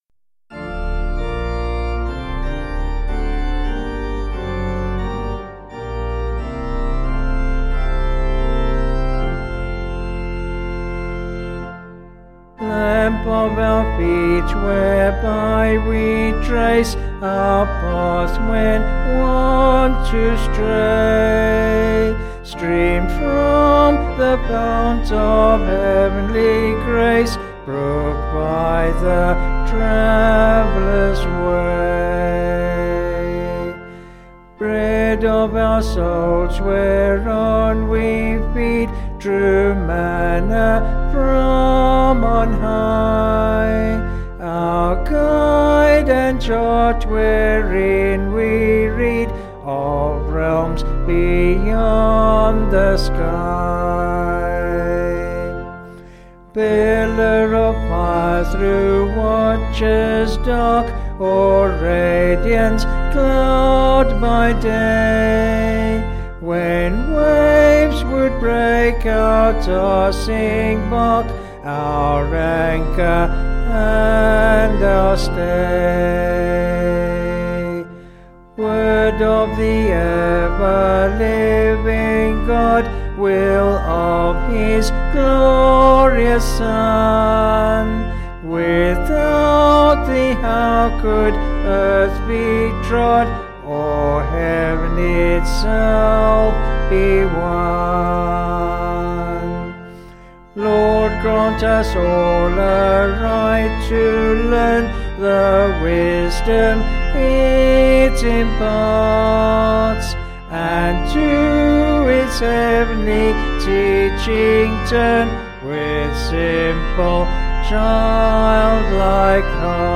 Vocals and Organ   263.6kb Sung Lyrics